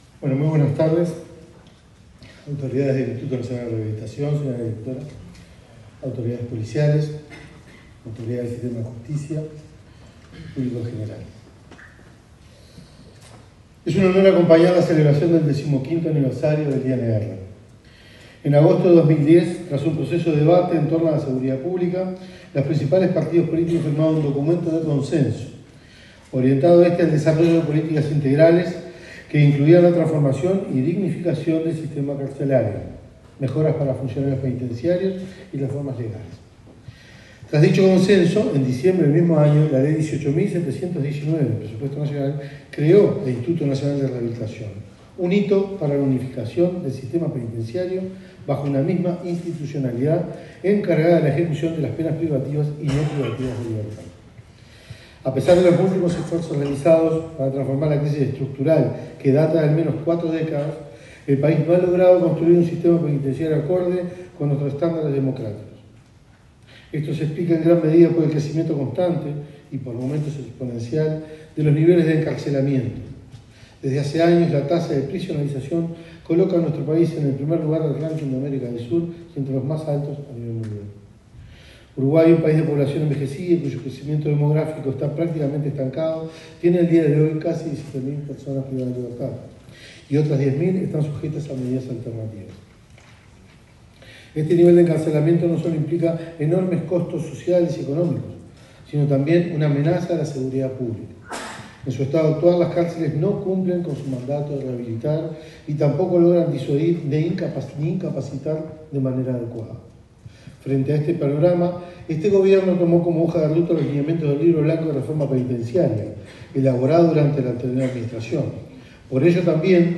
Palabras de autoridades en ceremonia por el 15.° aniversario del Instituto Nacional de Rehabilitación
Palabras de autoridades en ceremonia por el 15.° aniversario del Instituto Nacional de Rehabilitación 08/12/2025 Compartir Facebook X Copiar enlace WhatsApp LinkedIn El ministro del Interior, Carlos Negro y la directora Nacional del Instituto Nacional de Rehabilitación (INR), Ana Juanche, se expresaron en la ceremonia con motivo del 15.° aniversario del INR.